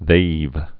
(thāv)